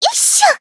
Taily-Vox_Attack3_jp.wav